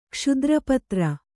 ♪ kṣudra patra